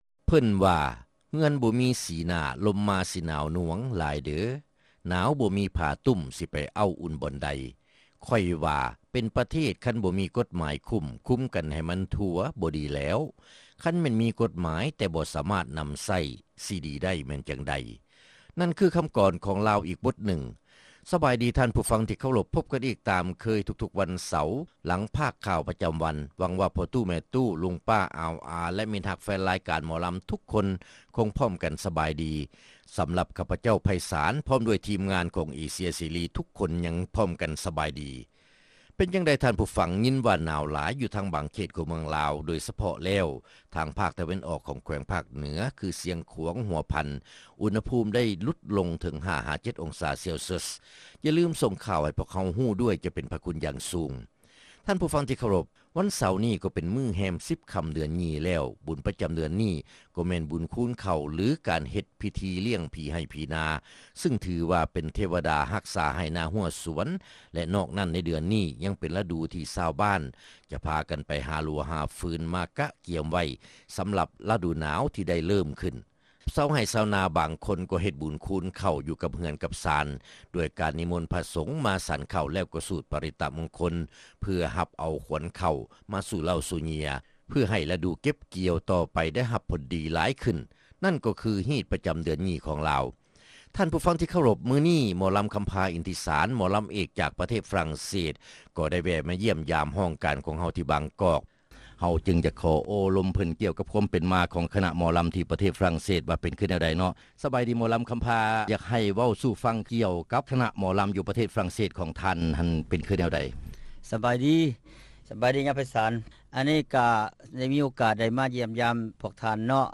ຣາຍການໜໍລຳ ປະຈຳສັປະດາ ວັນທີ 12 ເດືອນ ມົກະຣາ ປີ 2007